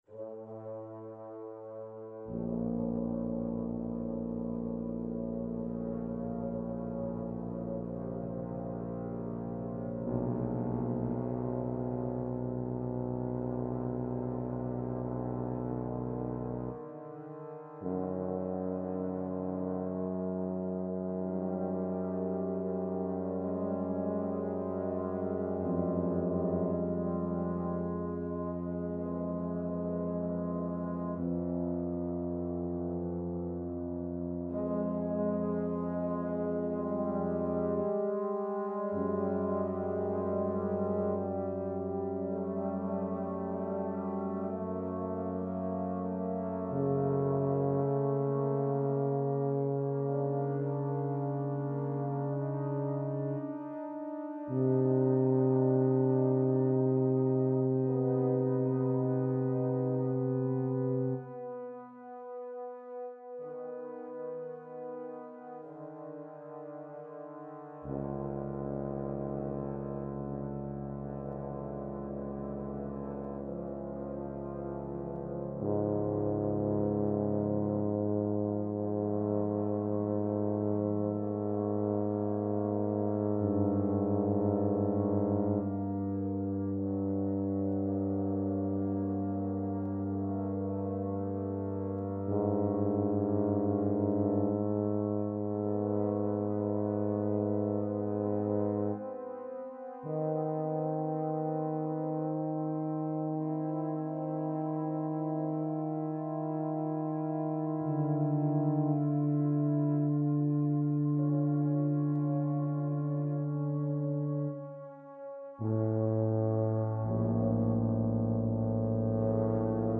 Tuba / euphonium quartet            2019        duration: 8 minutes
MIDI synthesis rendering: